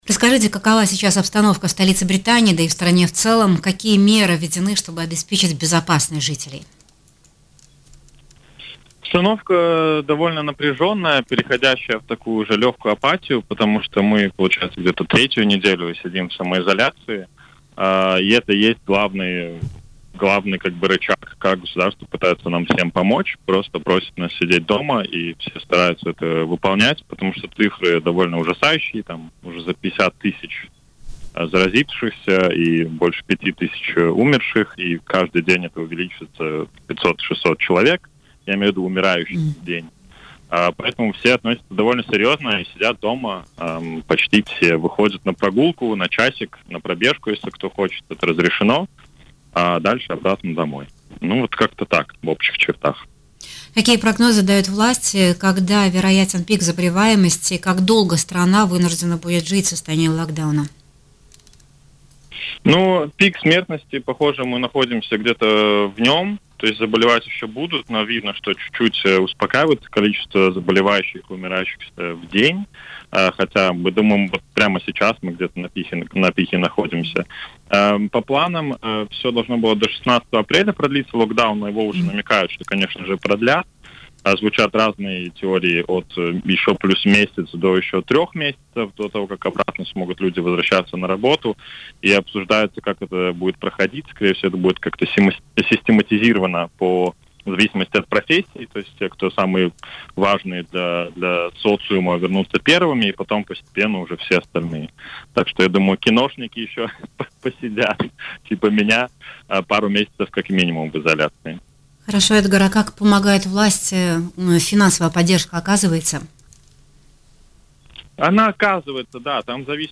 Великобритания находится на пике смертности от COVID-19 и пугающие цифры жертв коронавируса заставляют местных жителей сидеть в самоизоляции, несмотря на то, что финансовая помощь от властей поступит только в июне. Об этом в эфире радио Baltkom